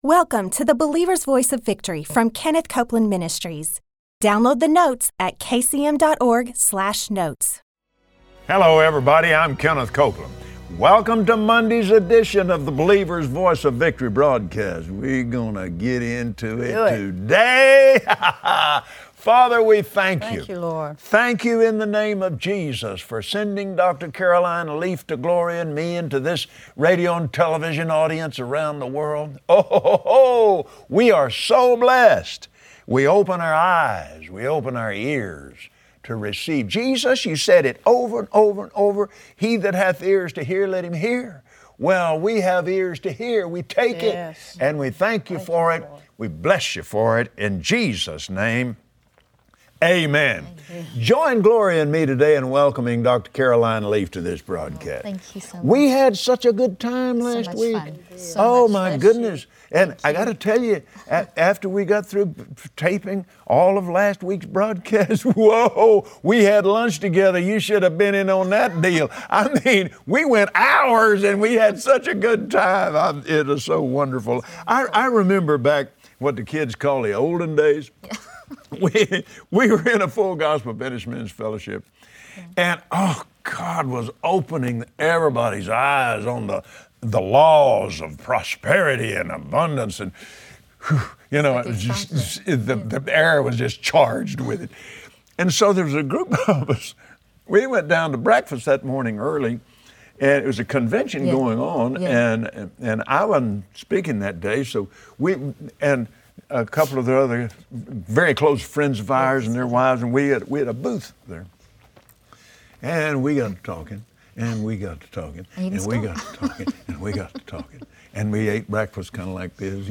Believers Voice of Victory Audio Broadcast for Monday 12/21/2015Next, on the Believer’s Voice of Victory, Kenneth and Gloria Copeland welcome Doctor Caroline Leaf, a well-known neuroscientist. Join them today as they reveal from science that you are what you eat and how your environment effects your body.